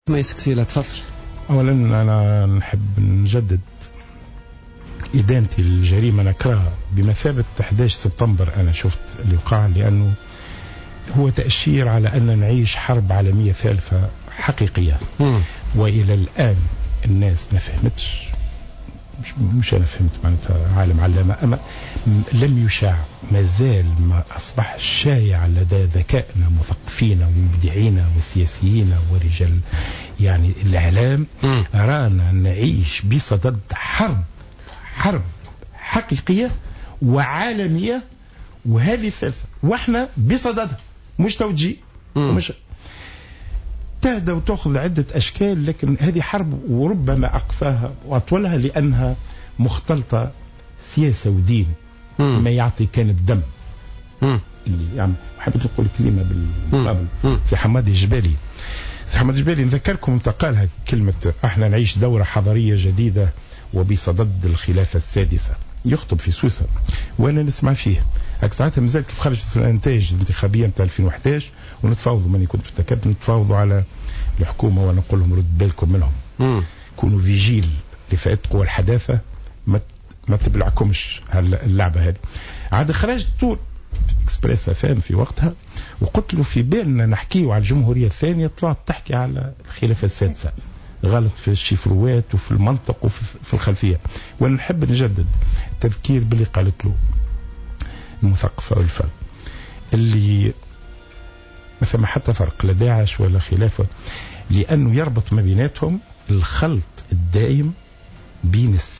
قال القيادي في حركة نداء تونس خميس قسيلة ضيف بوليتكا اليوم الثلاثاء 13 جانفي 2015 في تعليقه على الأحداث الأخيرة التي شهدتها فرنسا أن ما حدث تأشير على أن العالم يعيش حرب عالمية ثالثة ووصف ما يحدث في العالم من أحداث هو بمثابة الحرب الحقيقية التي ستدوم طويلا رغم أن الإعلام ليس واعيا الى حد الان بخطورتها على حد قوله.